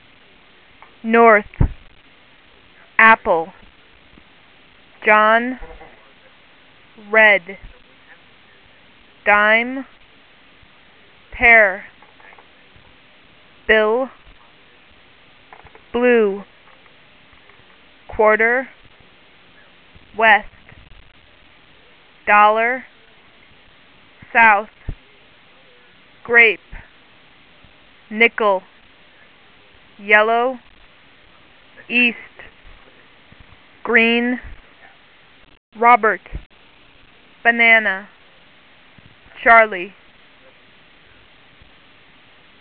Listen to the sound file. 20 words will be read with an interval of about one second between each word.